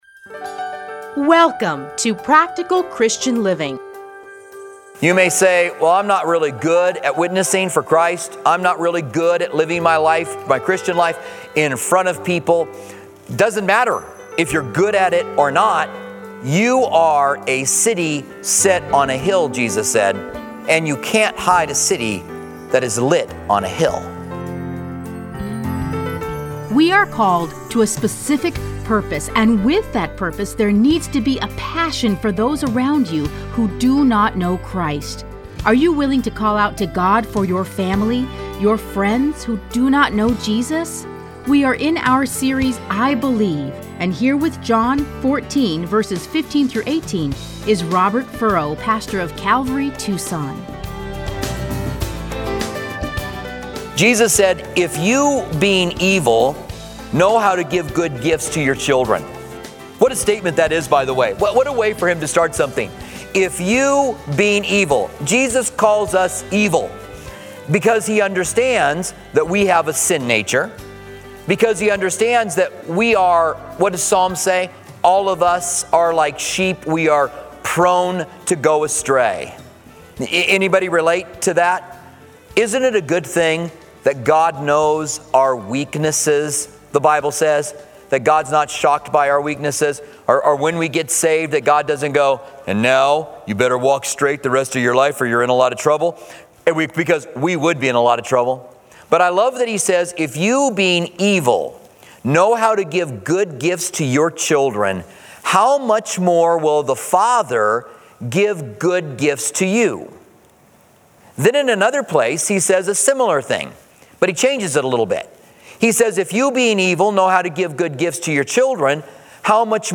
Listen to a teaching from John 14:15-19.